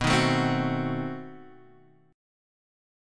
B7b9 chord